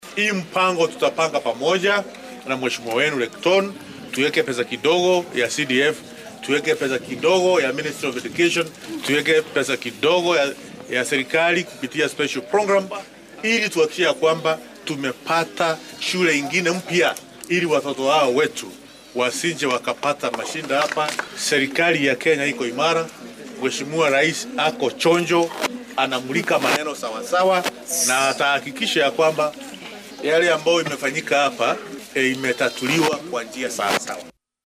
DHEGEYSO:Wasiir Ruku oo sheegay in dowladda ay ka go’an tahay cirib tirka musuqmaasuqa